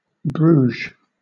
Ääntäminen
Southern England
IPA : /bɹuːʒ/